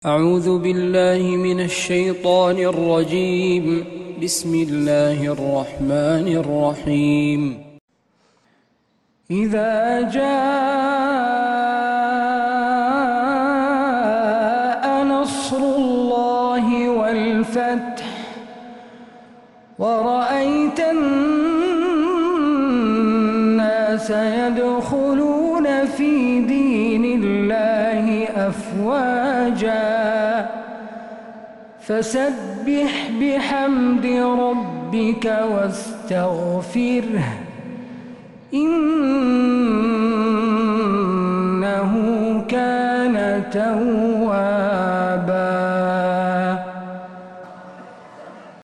من مغربيات الحرم النبوي